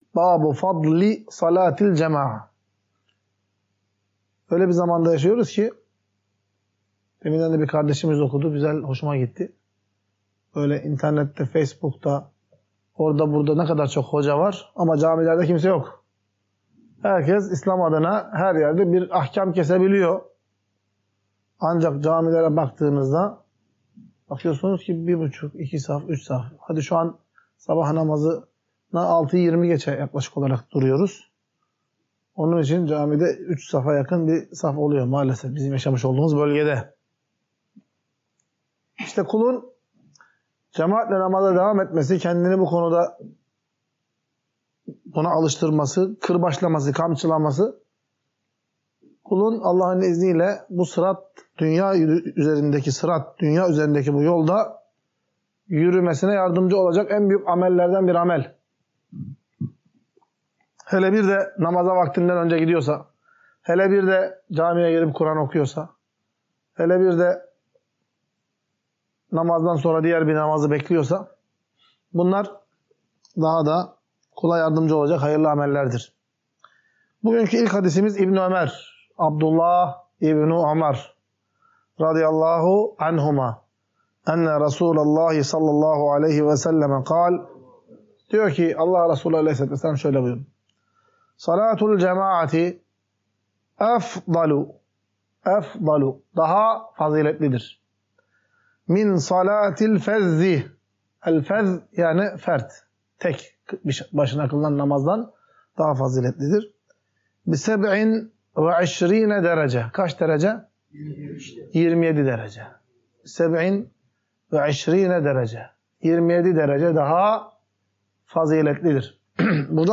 Ders - 12.